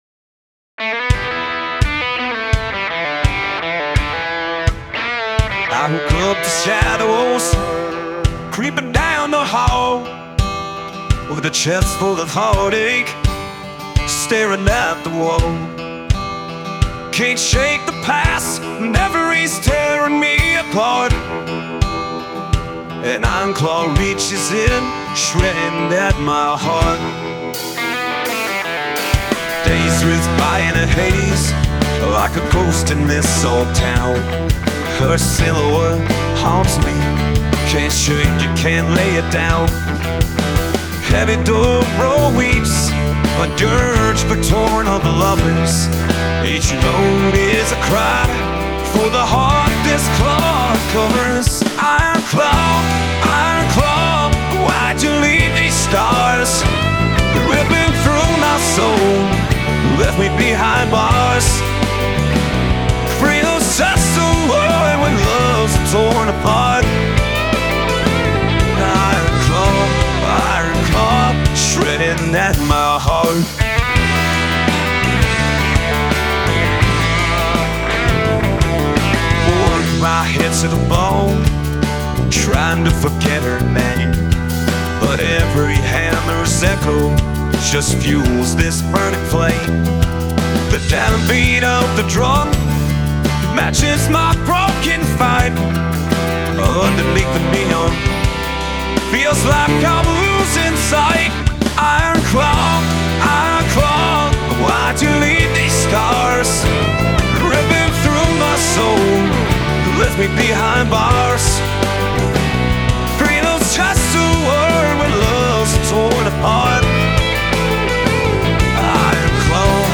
Iron Claw (Rock / Country) First song.
This is an online collaboration that I did with some other guys. I played guitars, programmed drums, Mixed and used AI vocals. Friends played keys and bass.